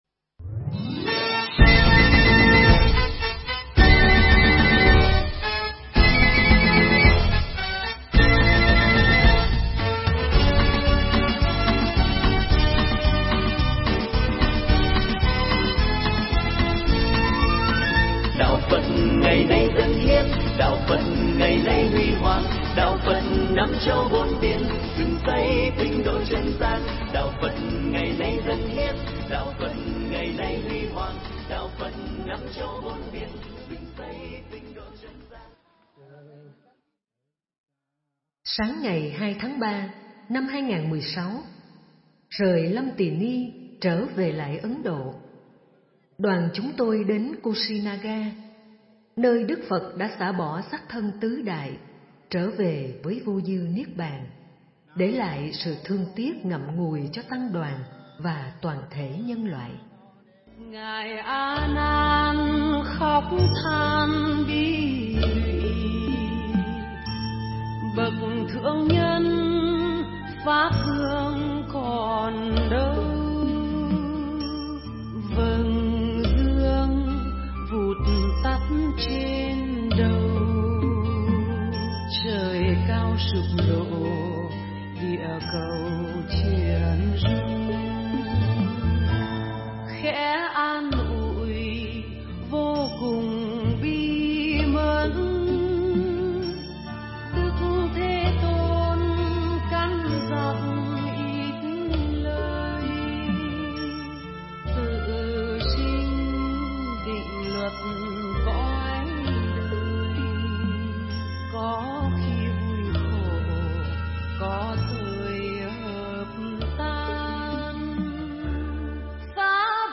Nghe mp3 Pháp Thoại Hành hương Phật tích Ấn Độ – Nepal tháng 03-2016 – DVD2 - Thầy Thích Nhật Từ tháng 3 năm 2016